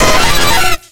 Cri d'Électhor dans Pokémon X et Y.